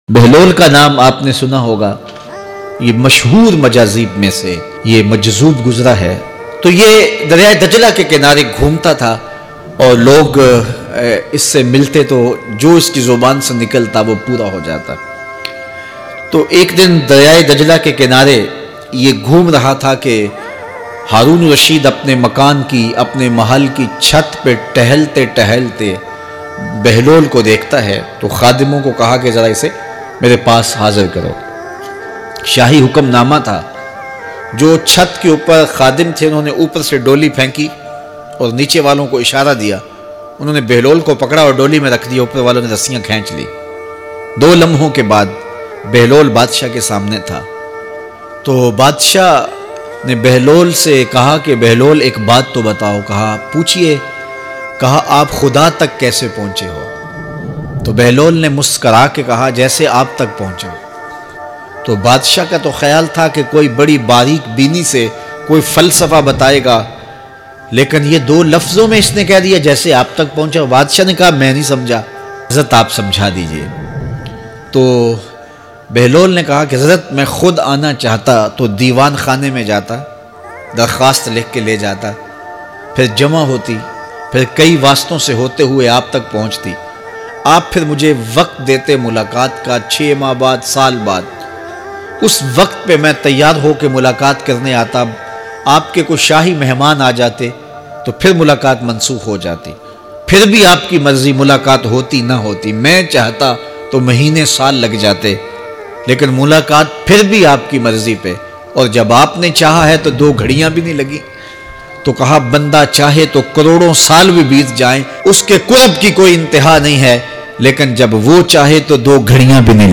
Hazrat behlol dana ka waqia bayan mp3